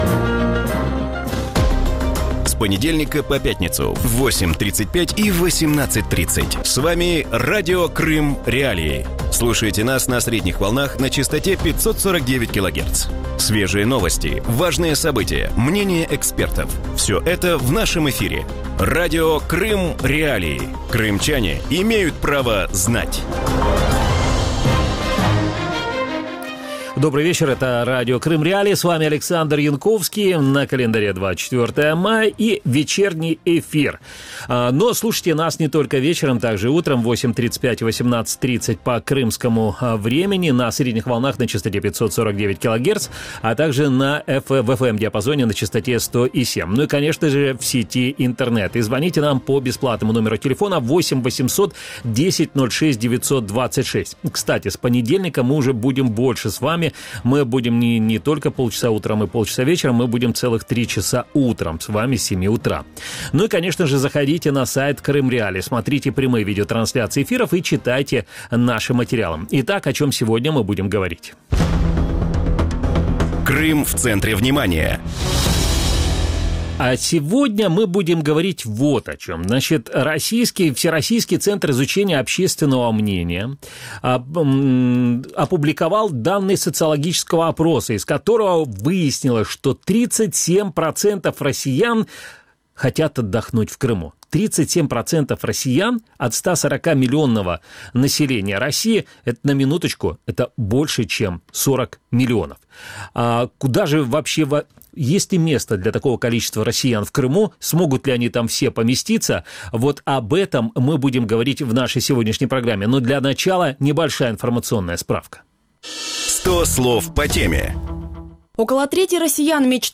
В вечернем эфире Радио Крым.Реалии обсуждают перспективы туристического сезона в аннексированном Крыму. Сколько туристов ожидают российские власти полуострова и сколько могут реально вместить крымские курорты?